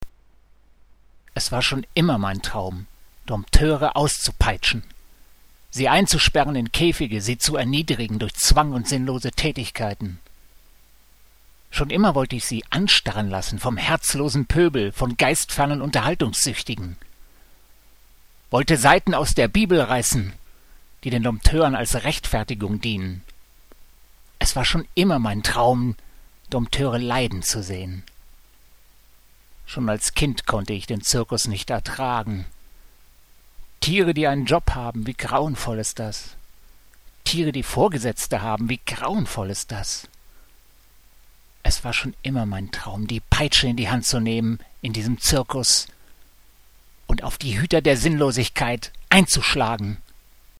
(Inwendig vorgetragen:)